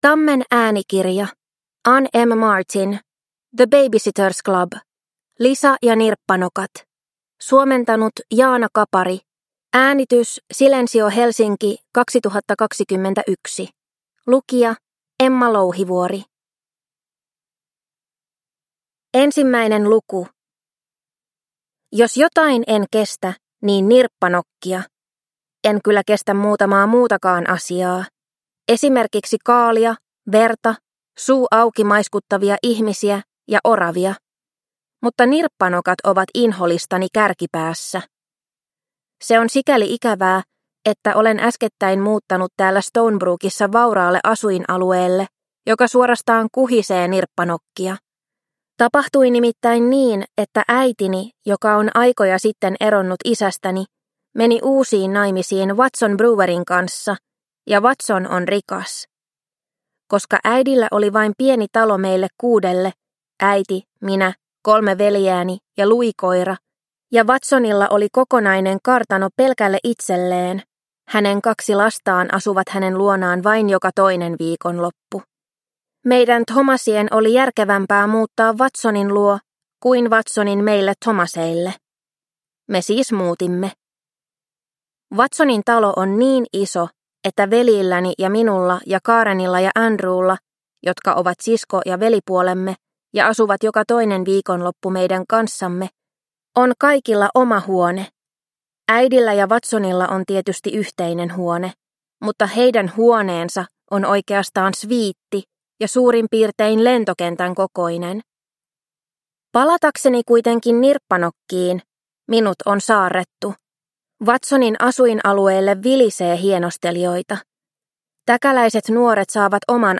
The Baby-Sitters Club. Lisa ja nirppanokat – Ljudbok – Laddas ner